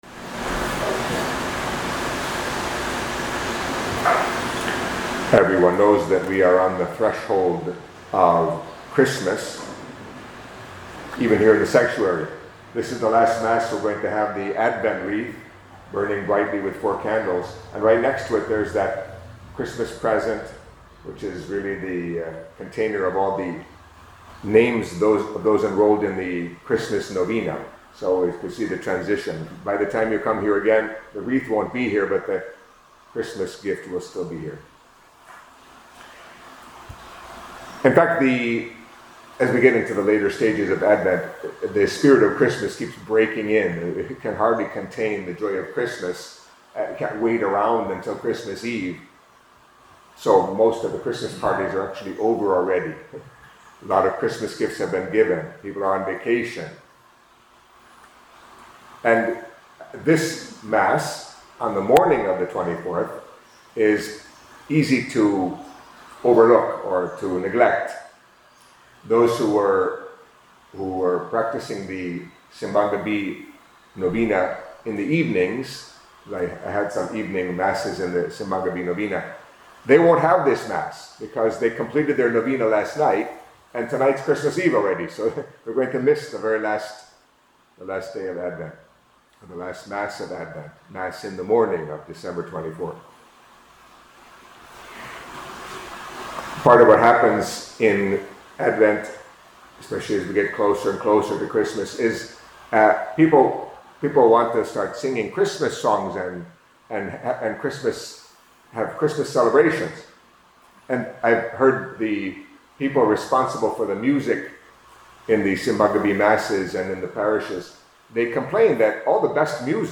Catholic Mass homily for Wednesday of the Fourth Week of Advent